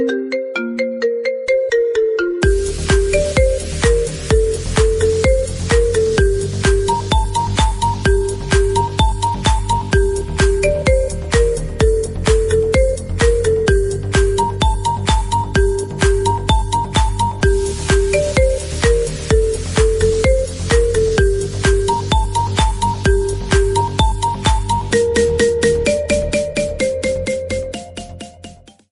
Category: Iphone Remix Ringtones